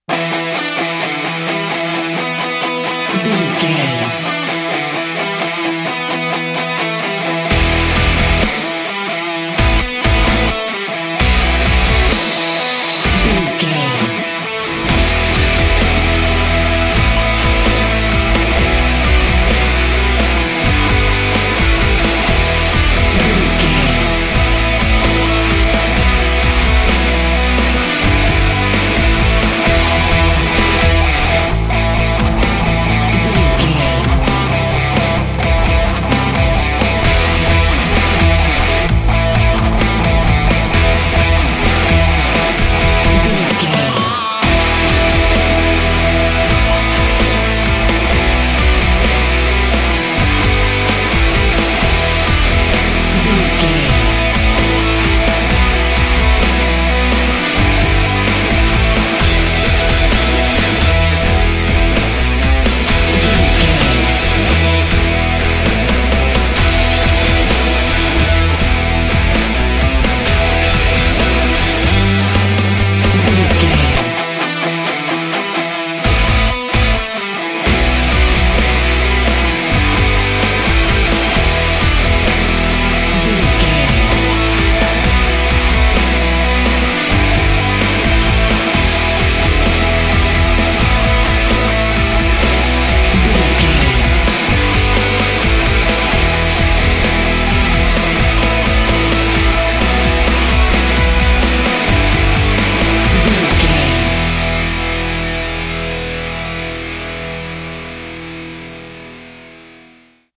A powerful hard rock theme track full of energy and glory.
Epic / Action
Fast paced
In-crescendo
Uplifting
Ionian/Major
hard rock
live guitars
aggressive
driving